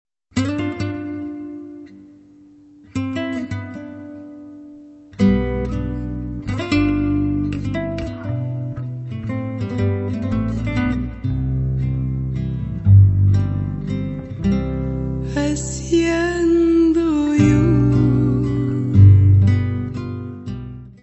voz
baixo semi-acústico, contrabaixo
percussões, bateria, samples, programação
: stereo; 12 cm